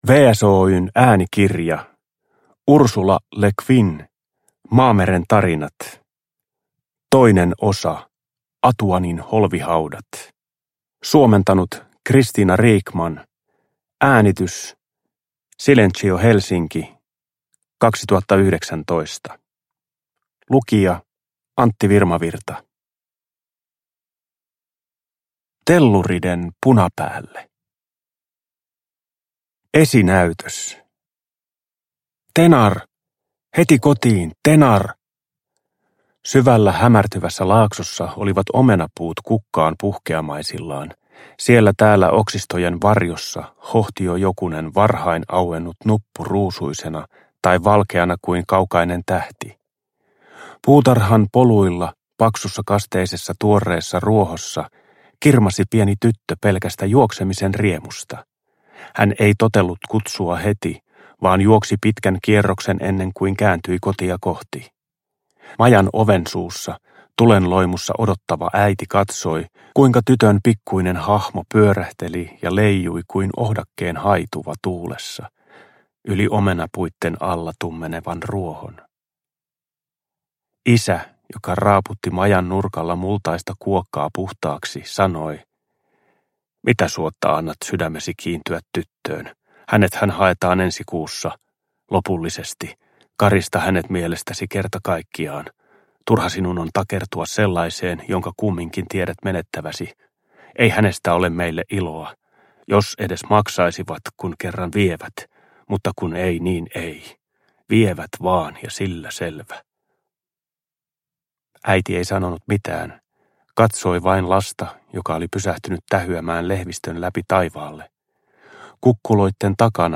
Atuanin holvihaudat (ljudbok) av Ursula K. Le Guin